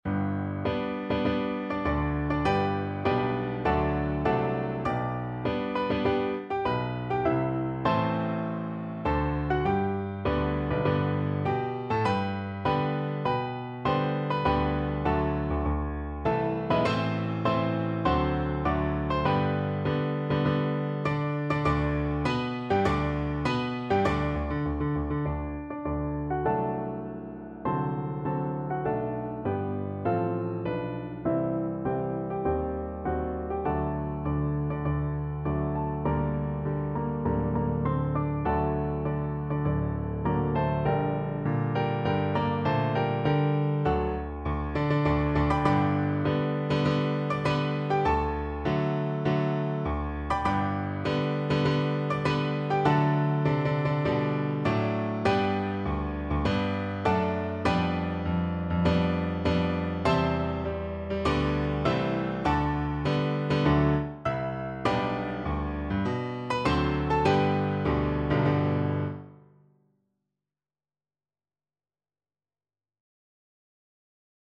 Piano Four Hands (Piano Duet) version
4/4 (View more 4/4 Music)
Alla marcia
Piano Duet  (View more Intermediate Piano Duet Music)